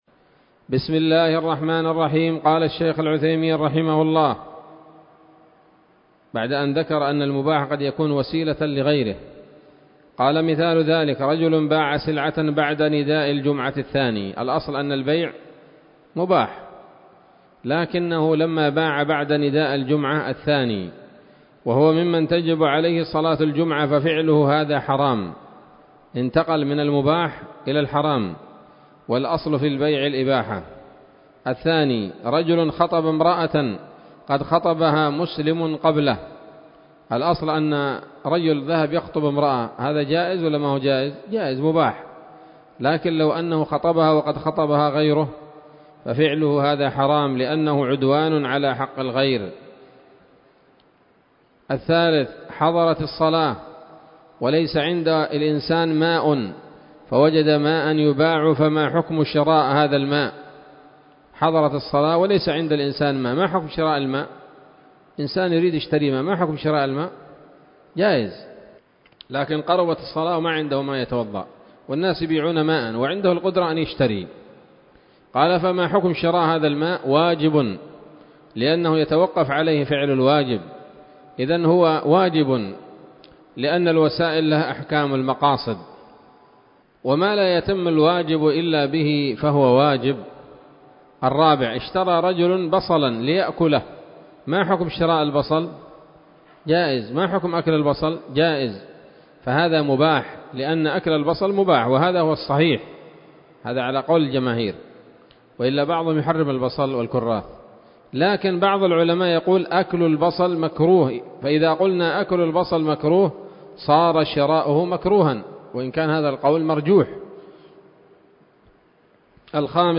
الدرس الثالث عشر من شرح نظم الورقات للعلامة العثيمين رحمه الله تعالى